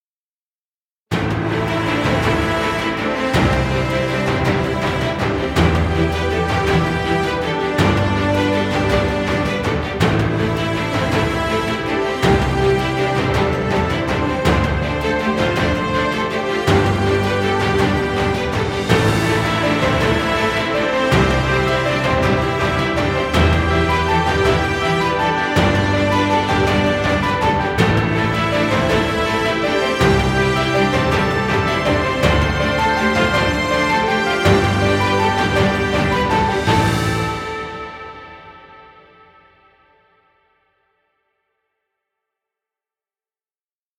Epic music.